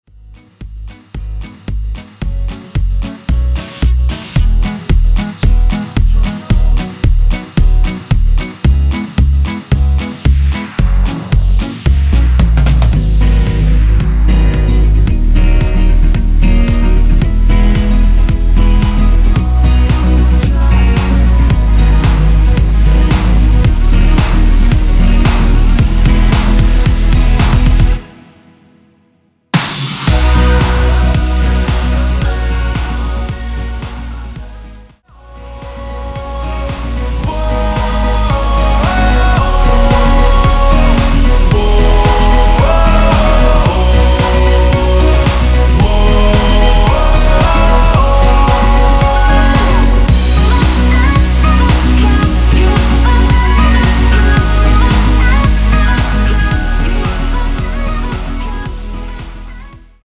원키에서(-1)내린 코러스 포함된 MR입니다.
앞부분30초, 뒷부분30초씩 편집해서 올려 드리고 있습니다.
중간에 음이 끈어지고 다시 나오는 이유는